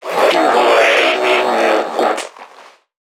NPC_Creatures_Vocalisations_Infected [125].wav